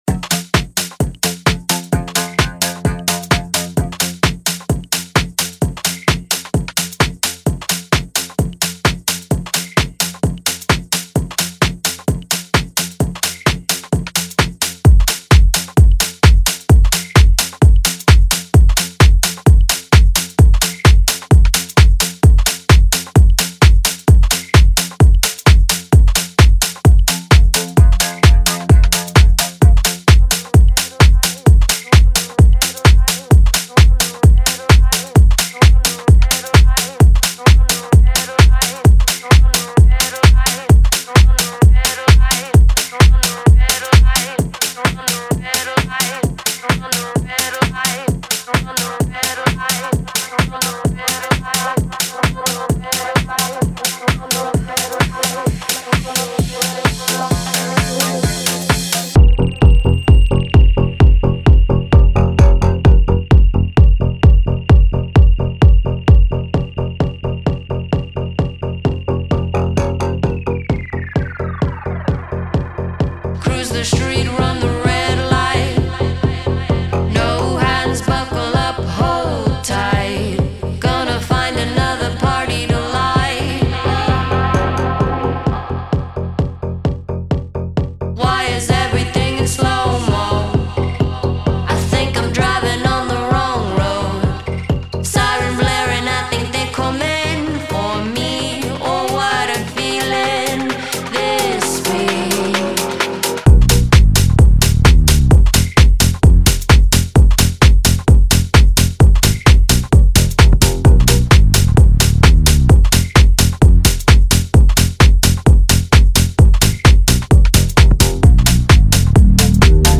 Some after-hours music